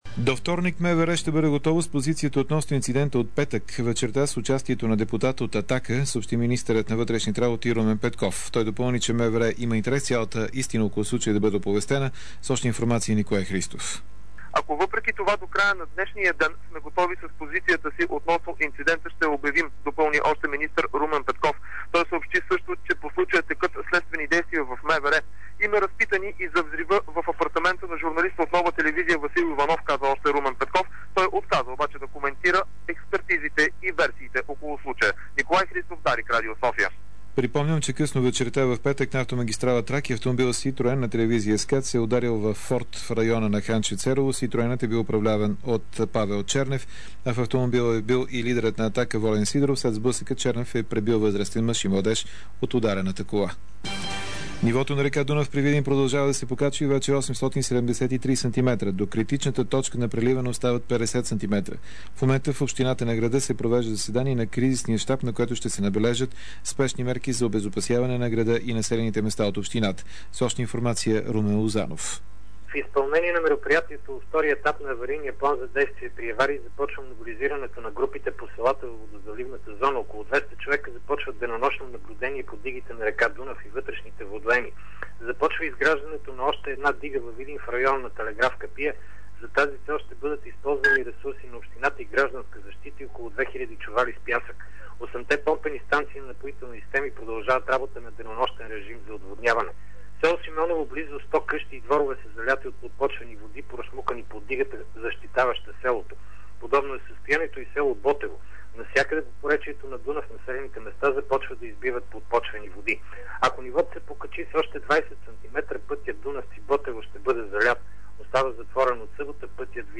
DarikNews audio: Обедна информационна емисия 10.04.2006